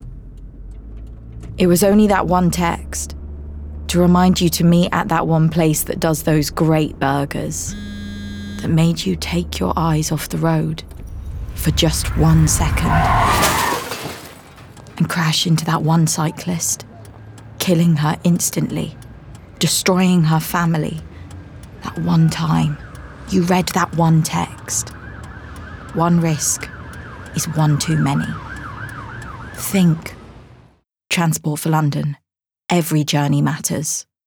RP ('Received Pronunciation')
Serious, Emotions, Informative